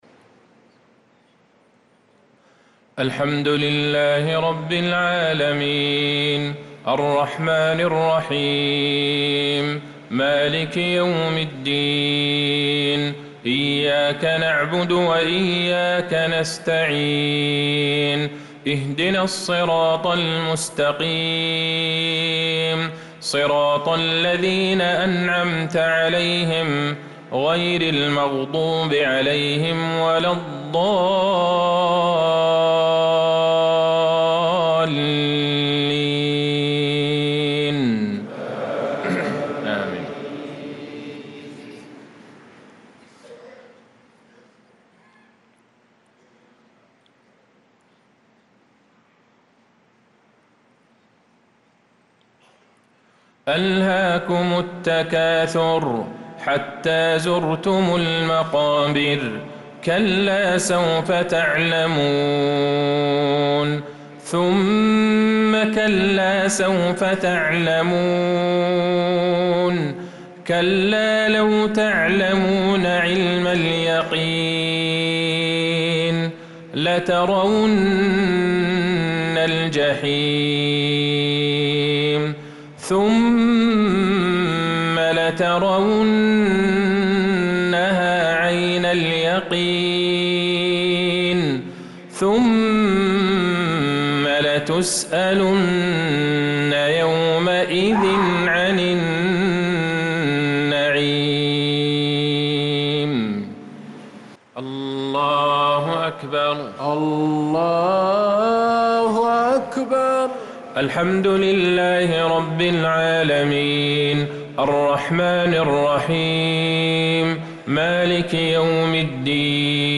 صلاة المغرب للقارئ عبدالله البعيجان 18 ذو الحجة 1445 هـ
تِلَاوَات الْحَرَمَيْن .